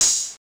561 - shawty [ open-hat ].wav